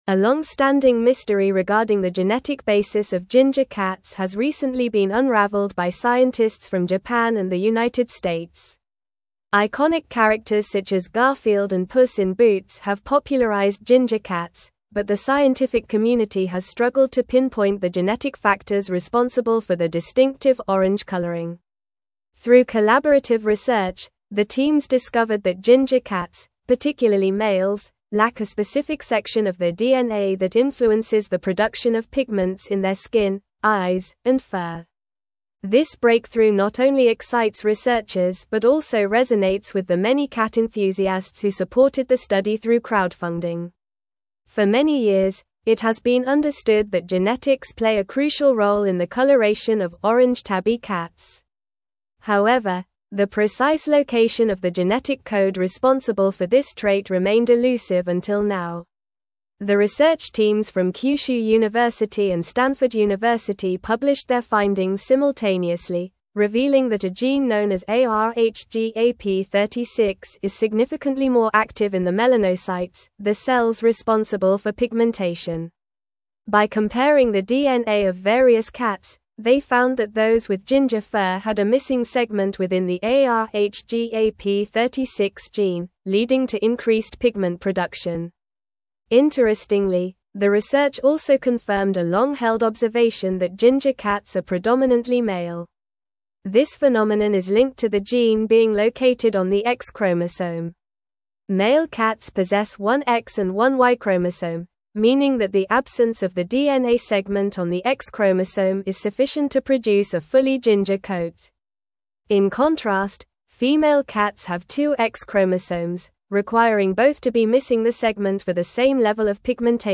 Normal
English-Newsroom-Advanced-NORMAL-Reading-Genetic-Mystery-of-Ginger-Cats-Unveiled-by-Researchers.mp3